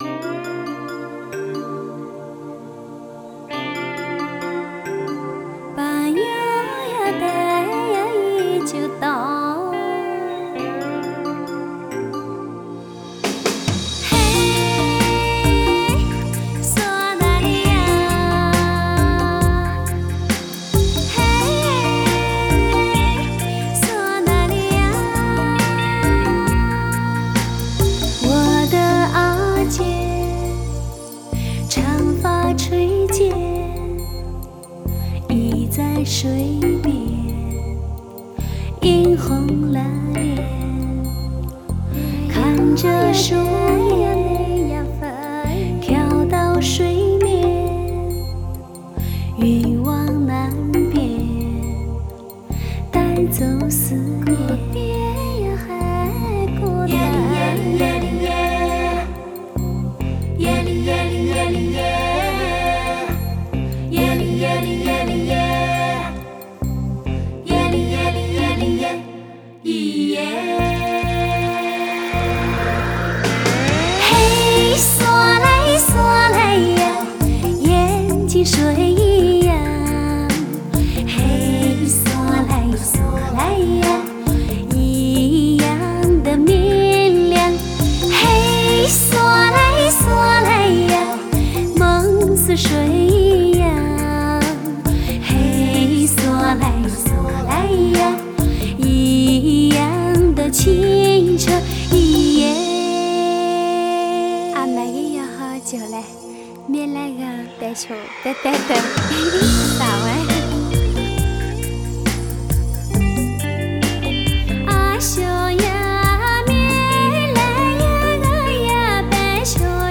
Жанр: Modern Traditional / Cinese pop / Miao folk